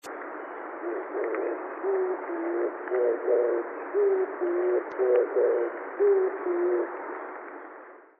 [キジバト]
低い声で「デェデェーポッポー」と、のどかに鳴く声が聞こえると、反射的に指定席に目が行きます。 よく聞いていると、決まって「デェデェーポッ」で終わります。
kijibato3.mp3